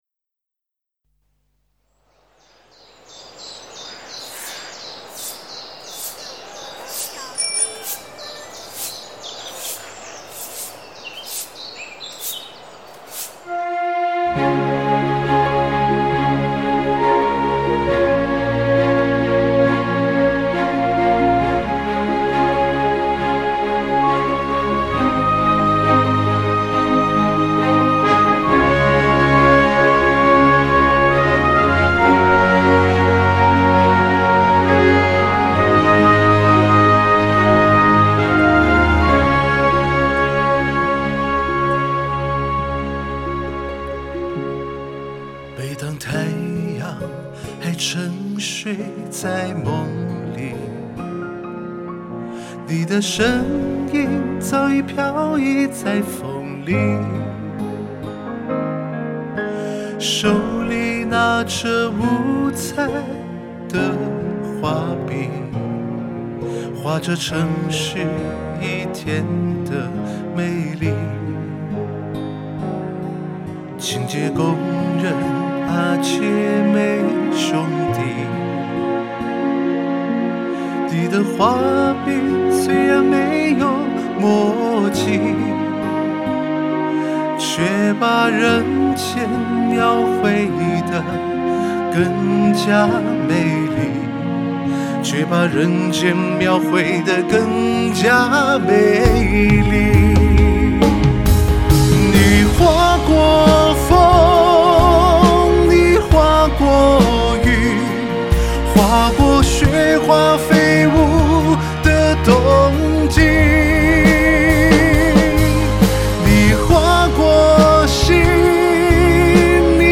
《画笔》这首歌歌词立意新颖，切入点好，巧妙地将清洁工人手中的扫帚比喻成画笔，以歌颂在城市建设中的一线环卫工人，他们无论严冬酷暑，起早贪黑，默默付出着，只为让我们生活的城市变得更加干净、亮丽。歌曲旋律通俗优美、易于传唱，是一首赞美以环卫工人为代表的一线劳动者、传递正能量的优秀行业歌曲。